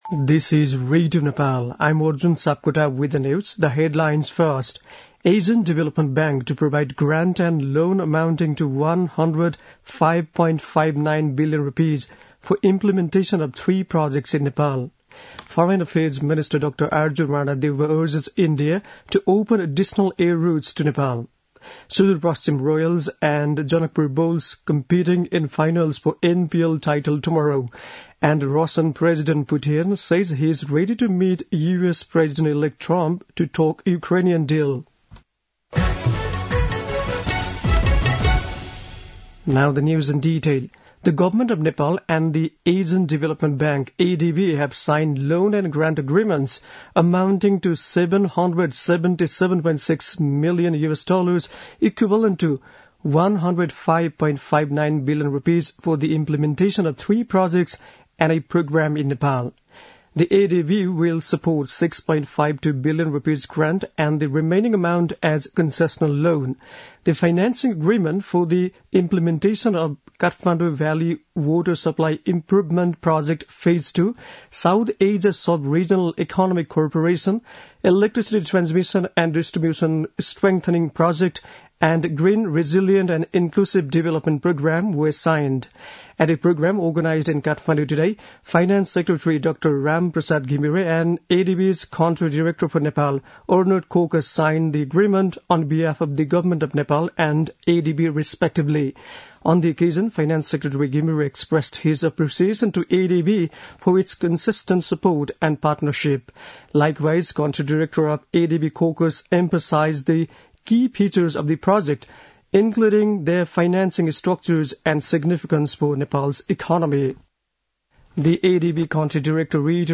बेलुकी ८ बजेको अङ्ग्रेजी समाचार : ६ पुष , २०८१
8-pm-english-news-9-05.mp3